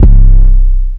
808 - Plugg.wav